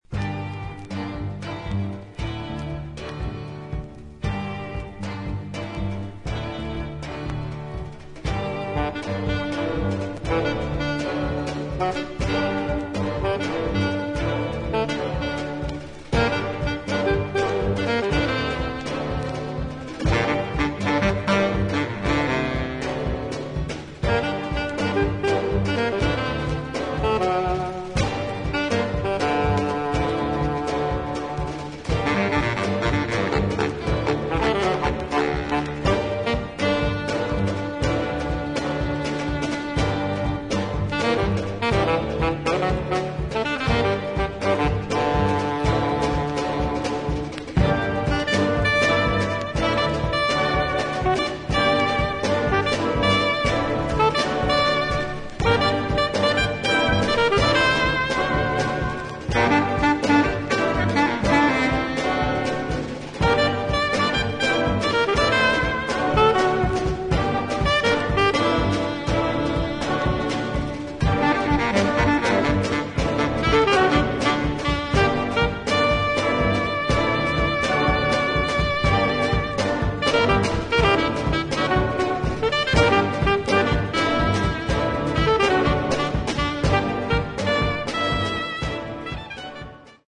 ダイナミックなオーケストラと自作打楽器により素晴らしいミニマリズムなアンサンブルを披露